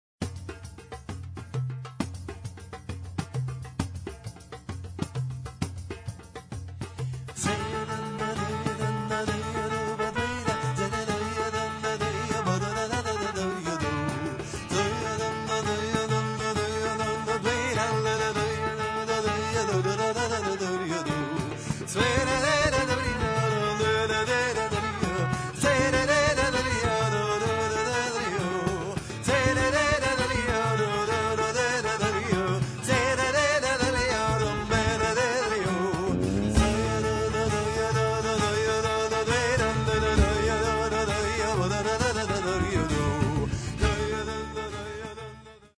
voce, harmonizer, loops, chitarra classica e acustica
tromba e filicorno
pianoforte, piano Rhodes e sint.